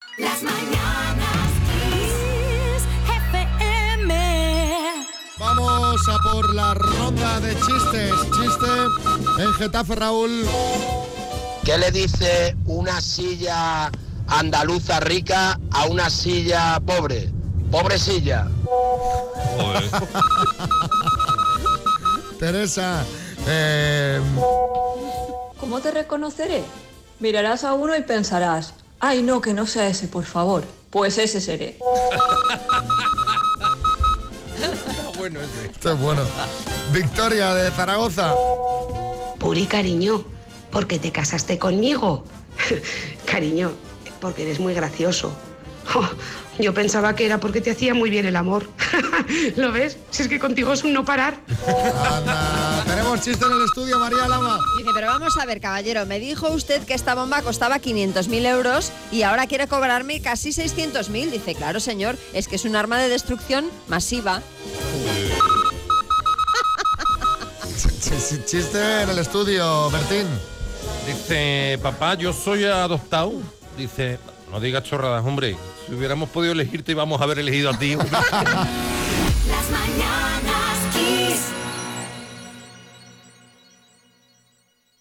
La ronda de chistes de hoy de nuestros oyentes pasa por Getafe y Zaragoza